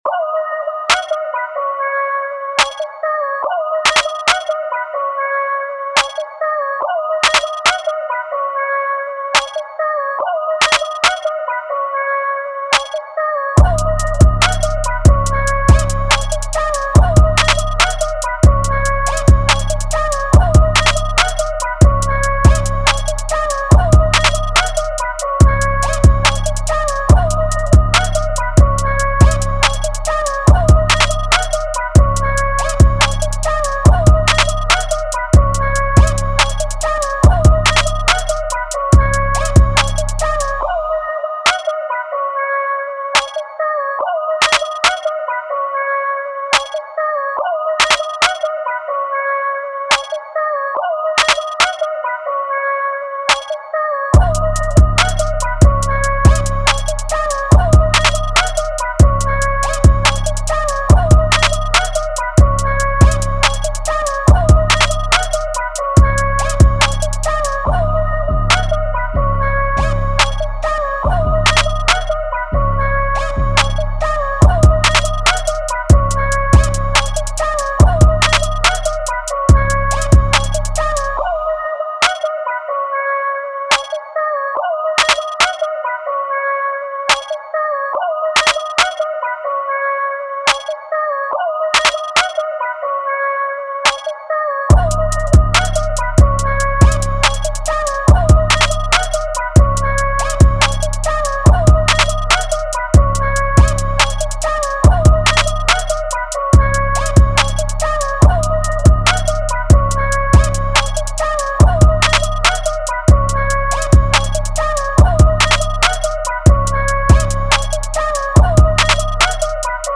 Hip Hop
AB Minor